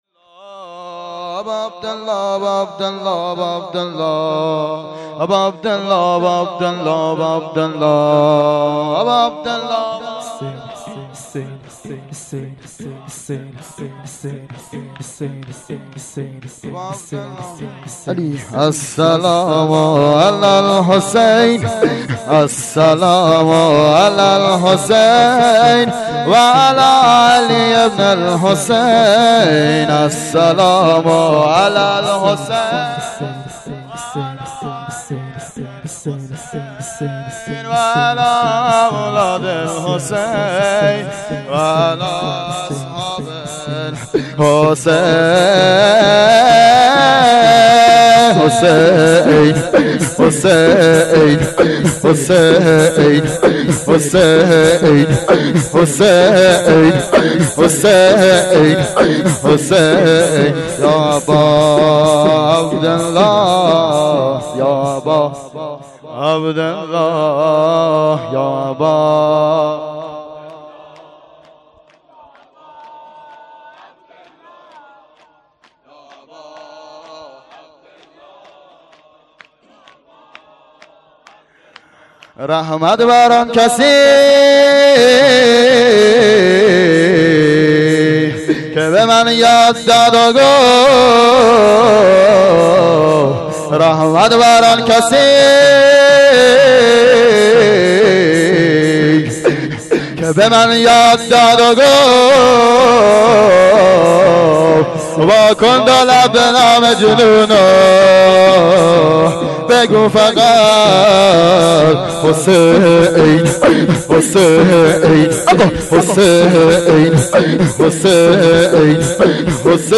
مراسم شهادت حضرت زهرا سلام الله علیها فاطمیه دوم ۱۴۰۳